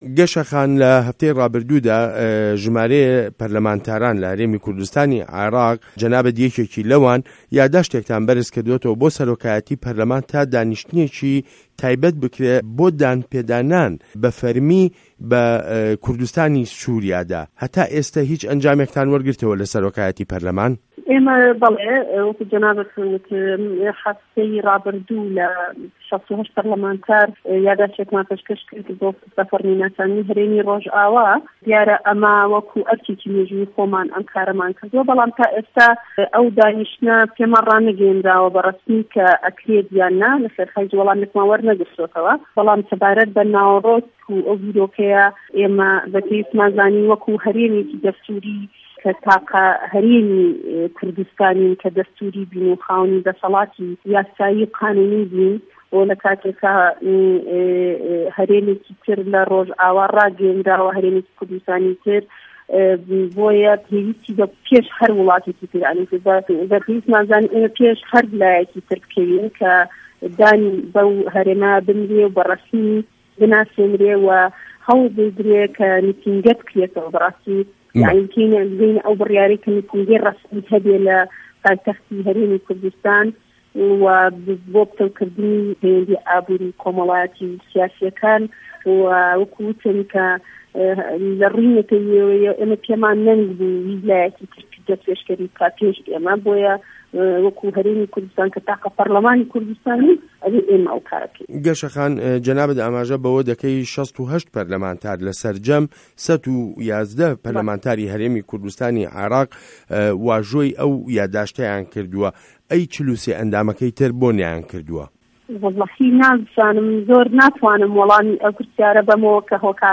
وتووێژ له‌گه‌ڵ گه‌شه‌ دارا حه‌فید